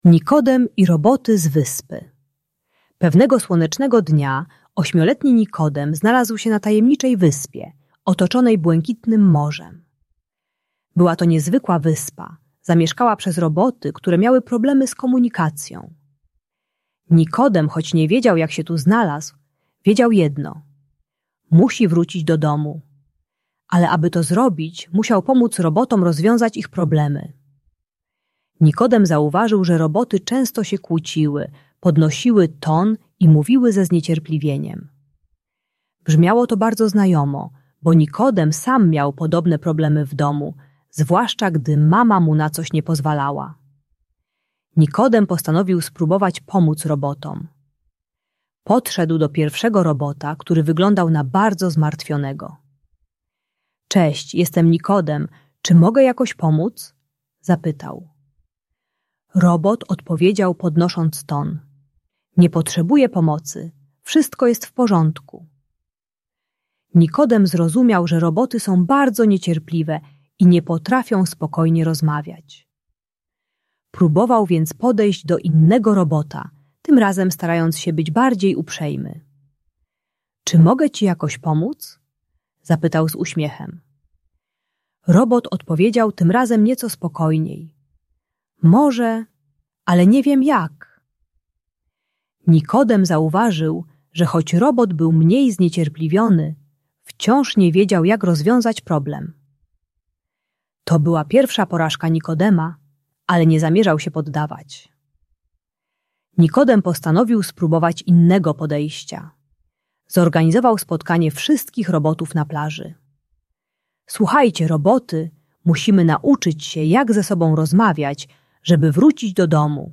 Historia Nikodema i Robotów z Wyspy - Agresja do rodziców | Audiobajka